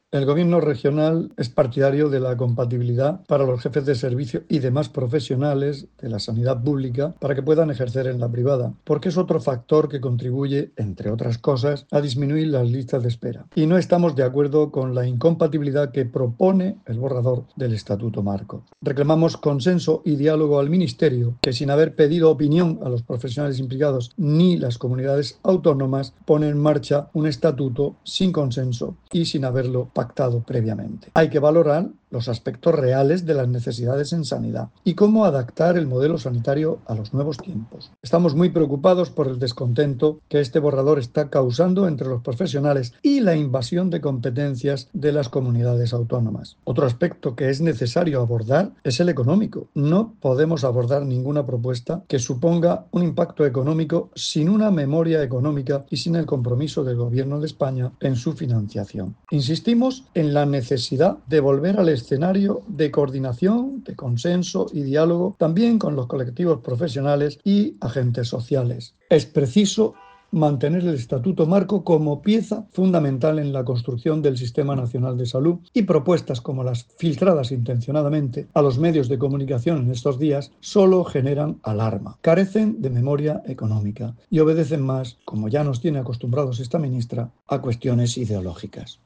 Declaraciones del consejero de Salud, Juan José Pedreño, con motivo de la reunión de la Comisión de Recursos Humanos del Sistema Nacional de Salud. [mp3]